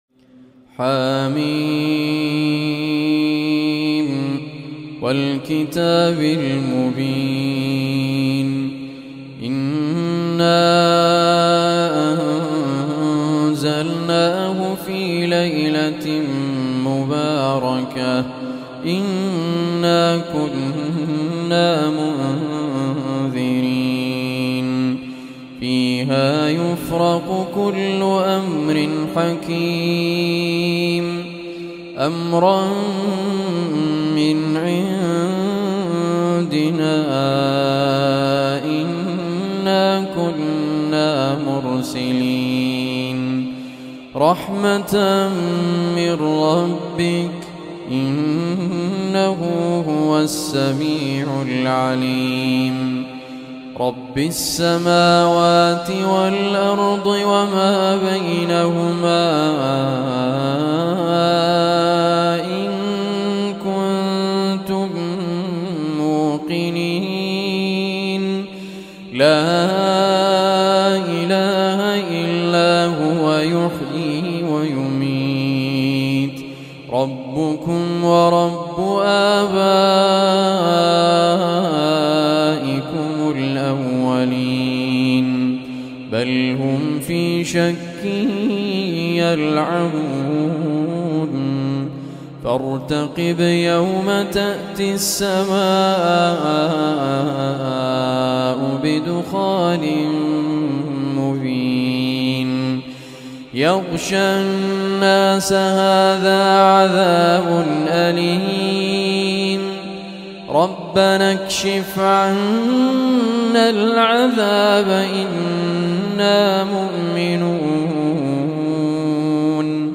Surah Dukhan MP3 Recitation
044-surah-ad-dukhan.mp3